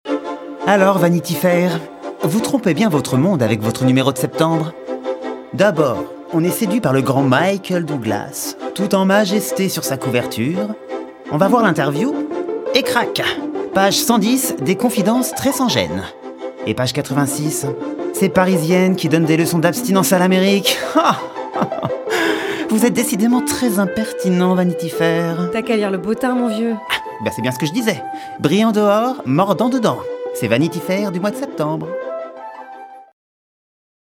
Voix off Pub - Vanity fair
15 - 45 ans - Baryton Ténor